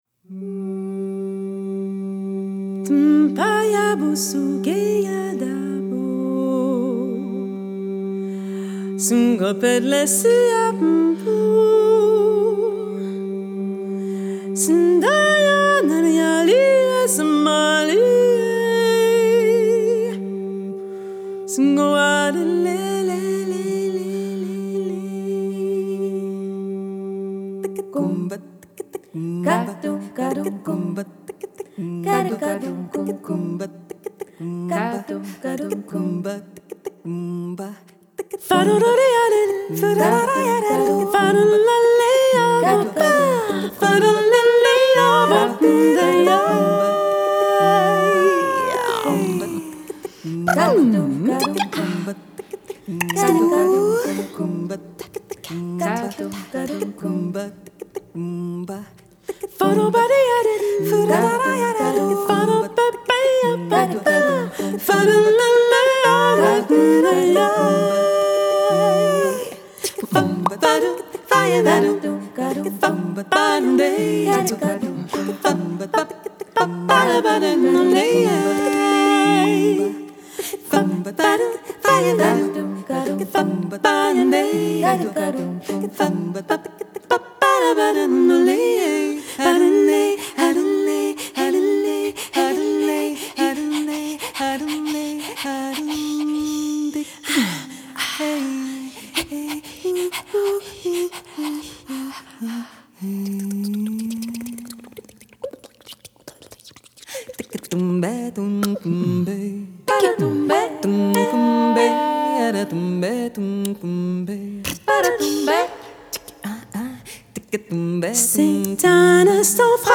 an evocative a cappella vocal quartet
they fashion lush harmonies and strong rhythmic drive
Genre: Jazz, Vocal, A Capella